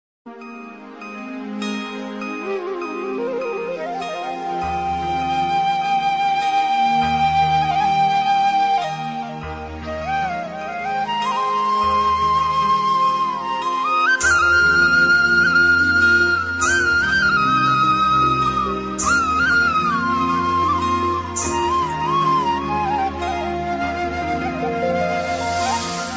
2 – موسيقى هندية كلاسيكية ورائعة